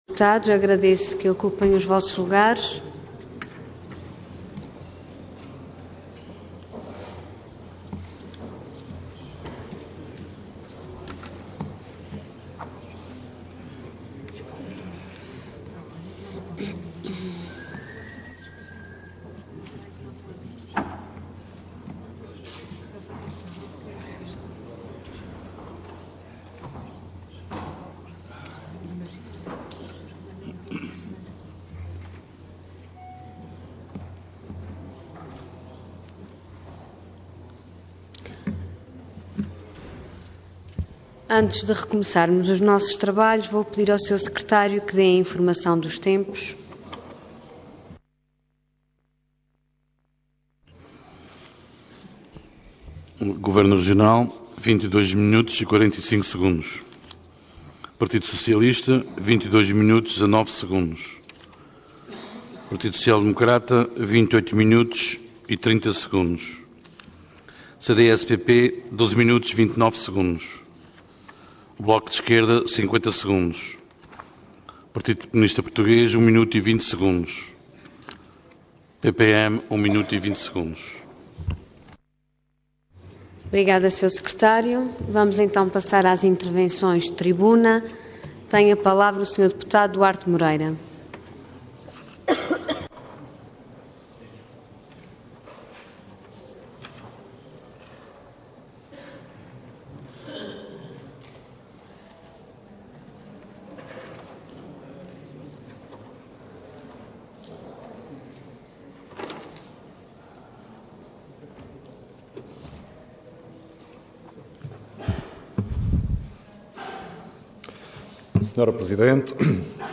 Intervenção Intervenção de Tribuna Orador Duarte Moreira Cargo Deputado Entidade PS